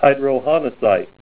Help on Name Pronunciation: Name Pronunciation: Hydrohonessite + Pronunciation
Say HYDROHONESSITE